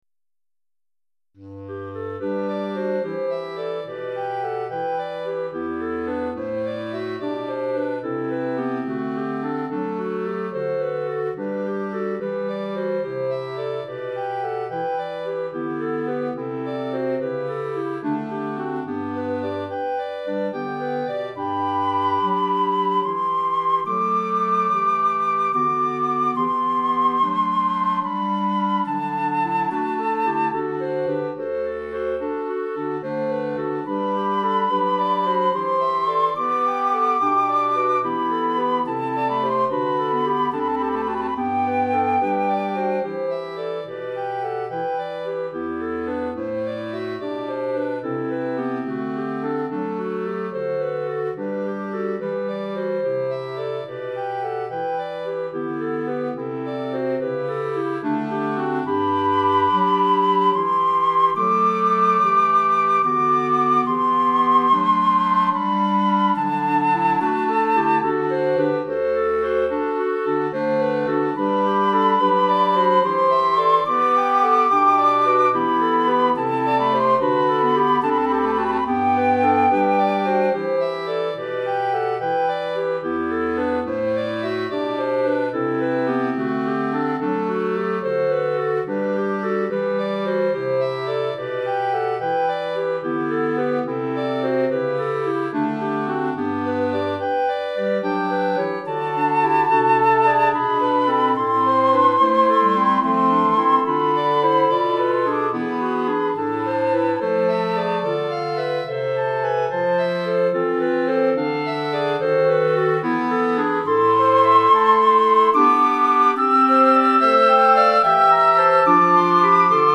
Flûte Traversière et 4 Clarinettes